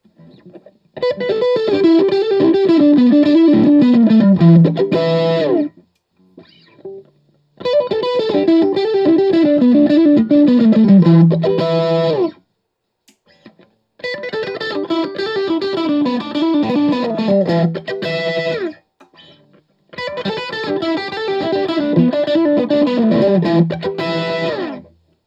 All recordings in this section were recorded with an Olympus LS-10.
For each recording, I cycle through all four of the possible pickup combinations, those being (in order): neck pickup, both pickups (in phase), both pickups (out of phase), bridge pickup.